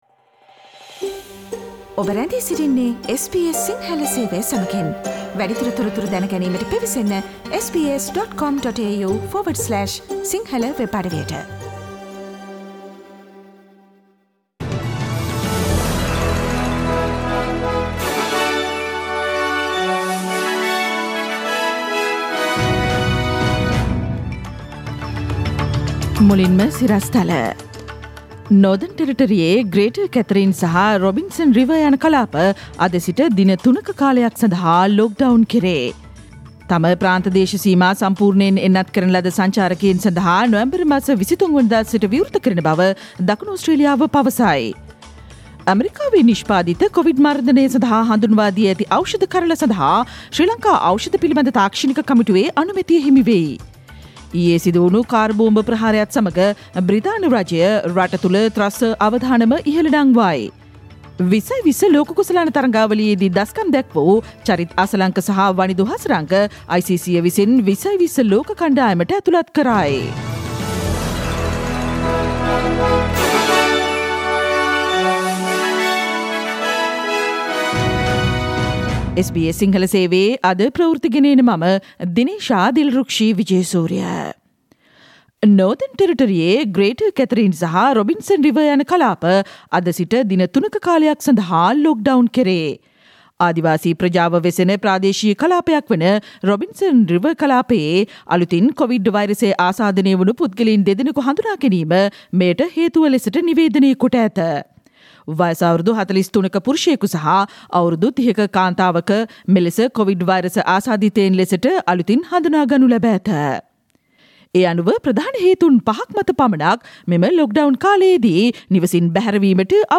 Click on the speaker mark on the photo above to listen to the SBS Sinhala Radio news bulletin on Tuesday 16th November 2021